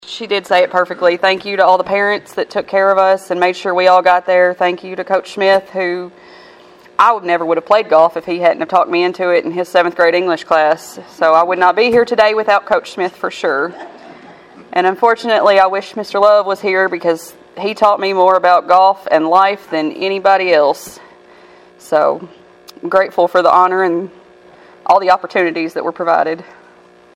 acceptance speech